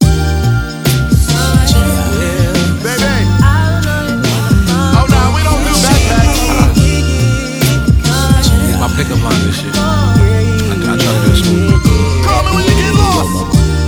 alarm2.wav